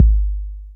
Kicks
DB - Kick (6).wav